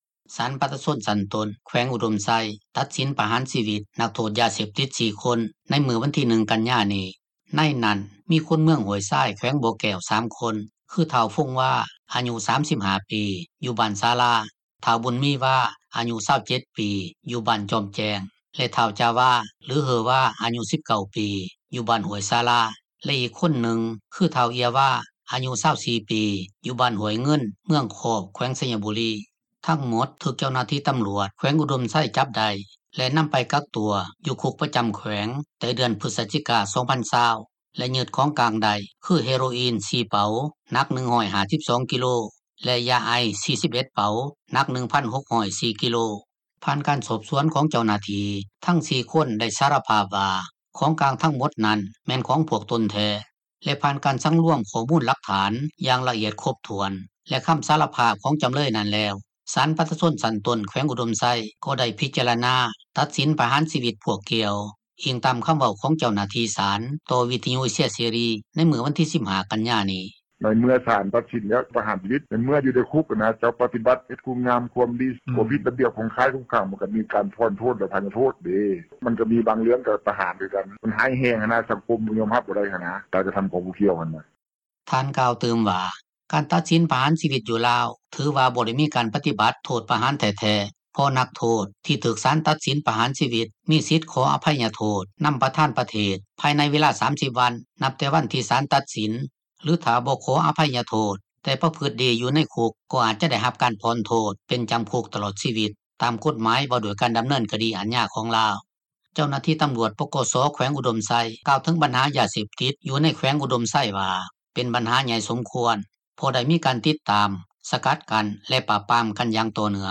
ກ່ຽວກັບເຣື່ອງການຕັດສິນປະຫານຊີວິດ ນັກໂທດຢາເສບຕິດຄັ້ງນີ້ ຊາວລາວຜູ້ນຶ່ງ ຢູ່ແຂວງອຸດົມໄຊ ເວົ້າຕໍ່ວິທຍຸເອເຊັຽເສຣີ ໃນມື້ວັນທີ 15 ກັນຍານີ້ວ່າ ປະຊາຊົນໃນແຂວງນີ້ ກໍເວົ້າກັນຫລາຍ ແຕ່ກໍບໍ່ໄດ້ສະແດງຄວາມຄິດເຫັນ, ເສີຍໆໄປ.